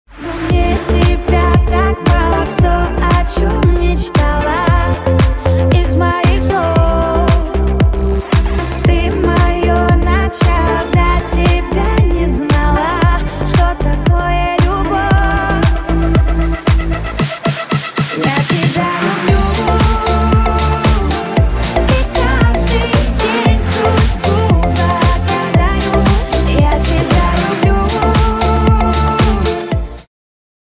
• Качество: 320, Stereo
поп
dance